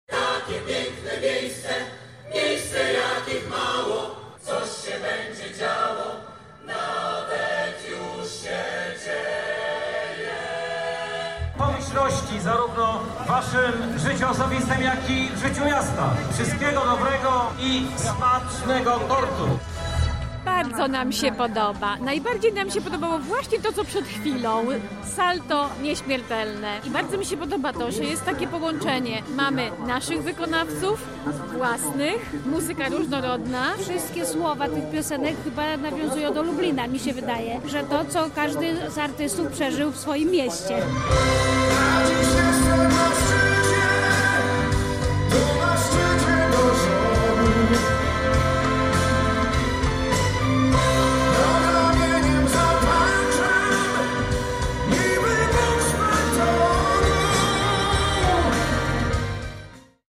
Na miejscu była nasza reporterka.